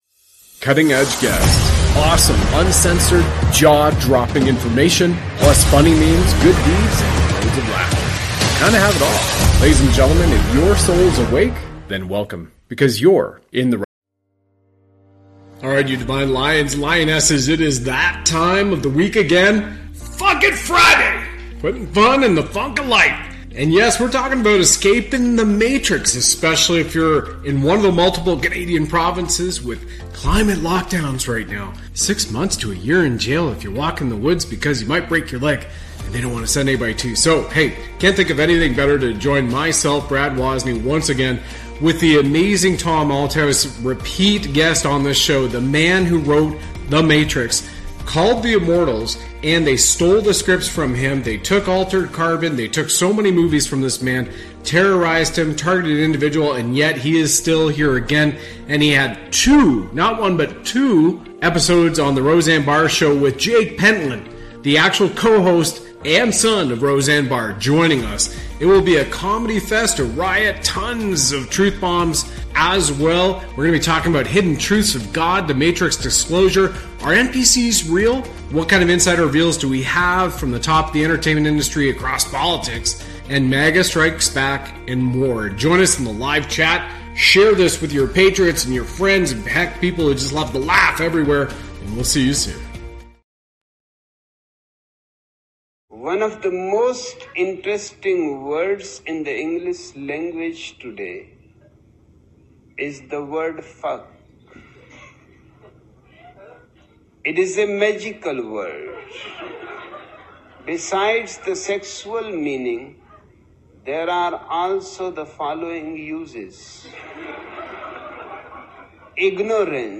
The conversation covers a range of topics, from escaping societal constraints to exploring hidden truths about God and the entertainment industry.